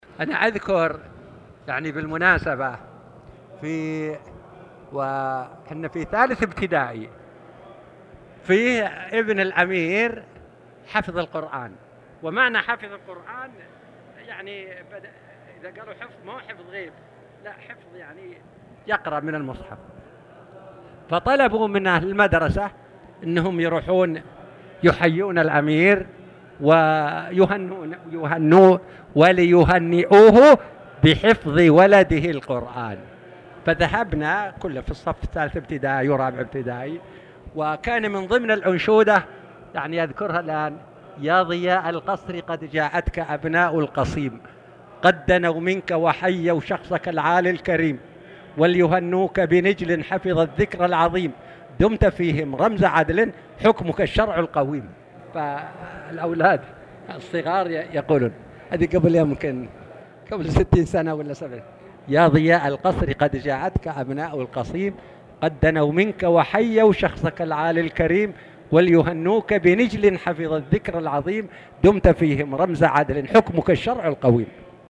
تاريخ النشر ٧ جمادى الأولى ١٤٣٨ هـ المكان: المسجد الحرام الشيخ